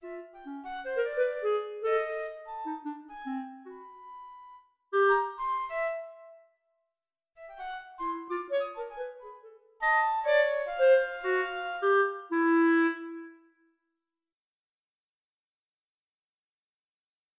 Hi Sometimes I also write very very short pieces just to try new sound comination, serial rows, etc...